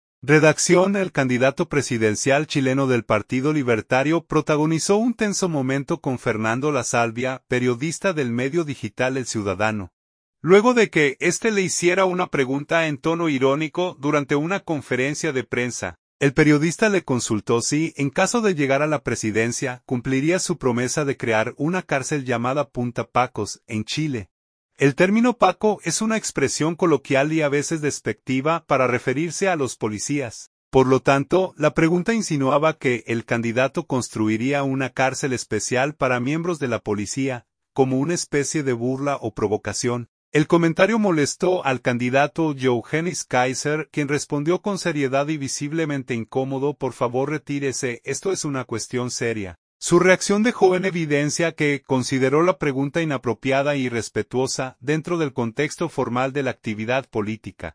El comentario molestó al candidato Johannes Kaiser, quien respondió con seriedad y visiblemente incómodo: “Por favor retírese, esto es una cuestión seria.”